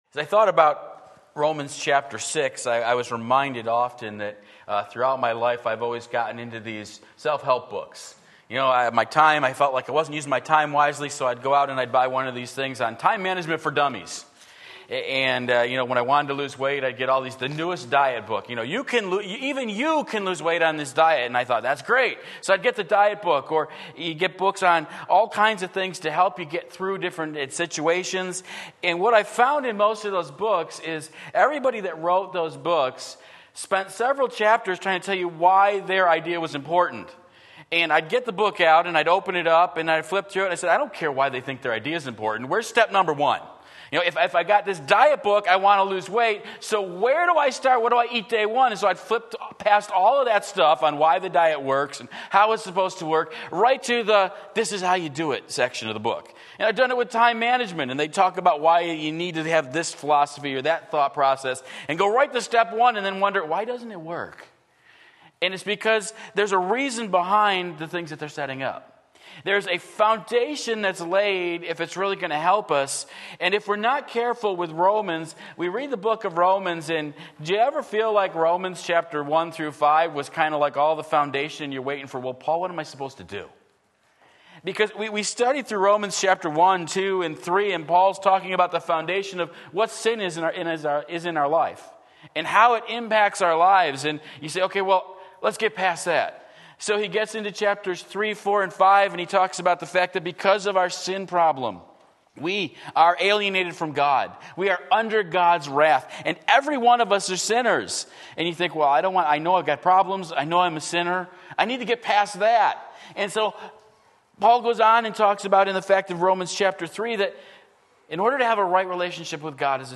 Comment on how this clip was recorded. Become What You Are Becoming Romans 6:11-14 Sunday Morning Service